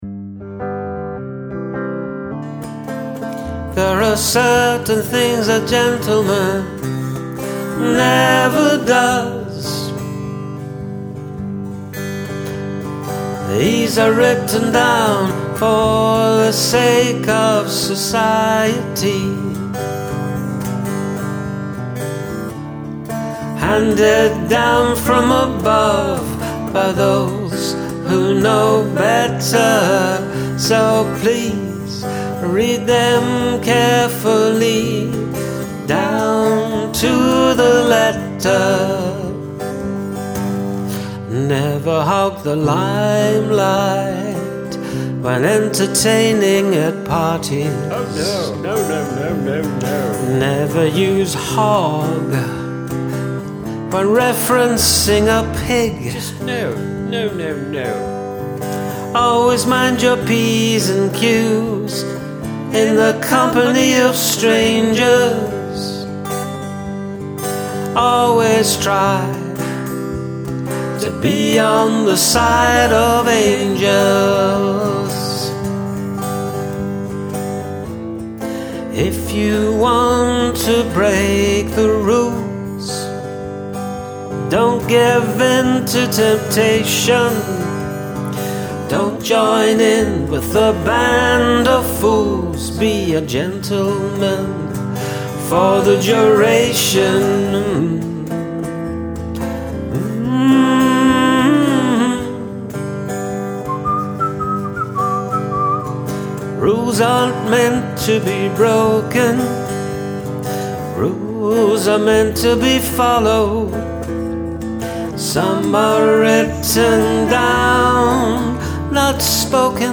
The backing vocals and comments are so great.